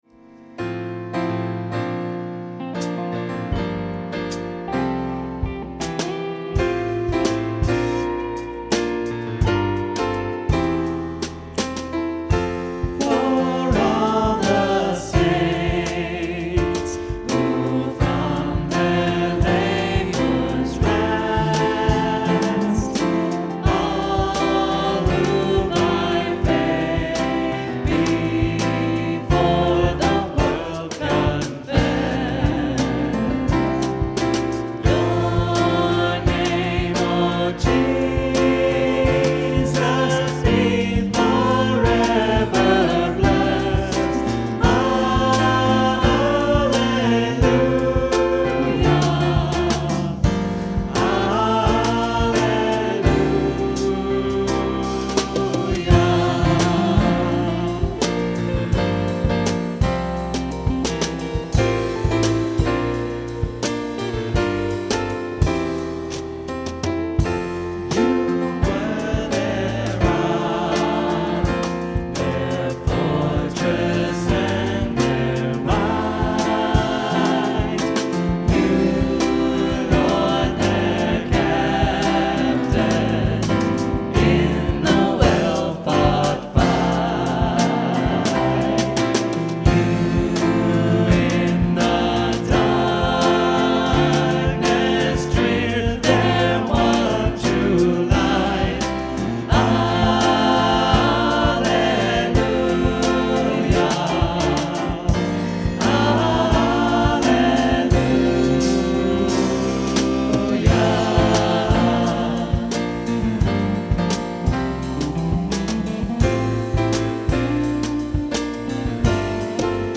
MP3 live version, 11/2/2008
Comments: I love how this melody and the words work with the slower tempo and jazzier chords BUT I have to tell you that I ran into a couple of "downsides" with this arrangement.
The live version posted above demonstrates a reasonable tempo.
for_all_the_saints_live.mp3